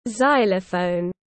Đàn phiến gỗ tiếng anh gọi là xylophone, phiên âm tiếng anh đọc là /ˈzaɪ.lə.fəʊn/
Xylophone /ˈzaɪ.lə.fəʊn/